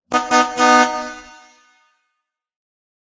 車のクラクション。
エアーホーン風の通知音。